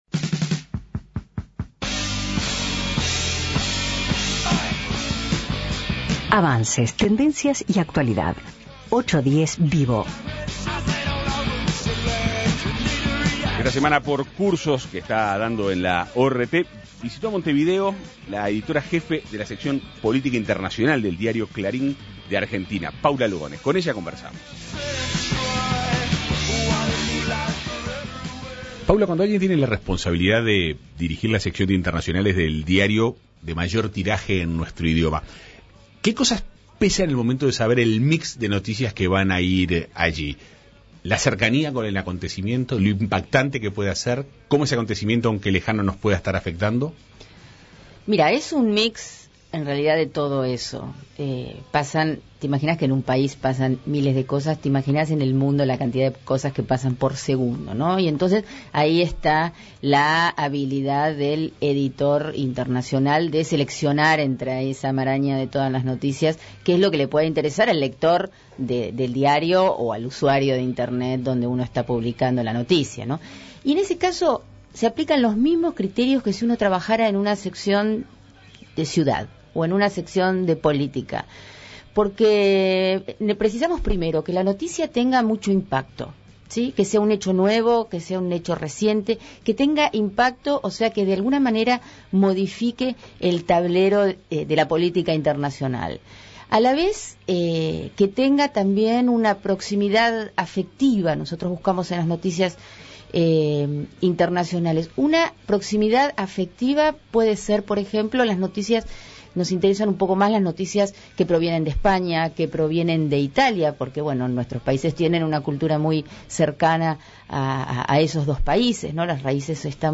810 VIVO Avances, tendencia y actualidad Entrevista